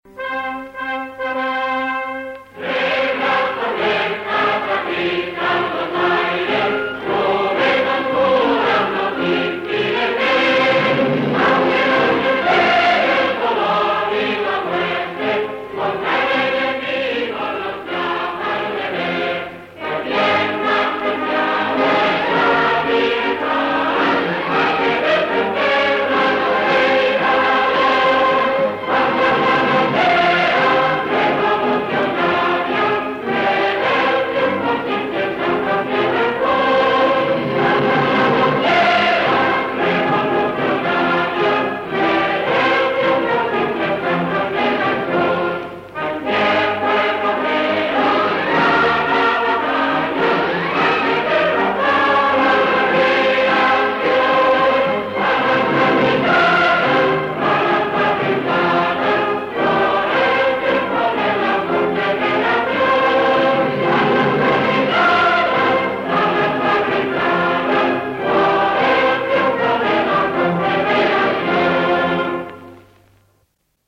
», l’hymne qui accompagne les images, il est interprété par l’Orfeó Català de Barcelone et a été enregistré en 1936.
Fichier mp3 de l’enregistrement fait en 1936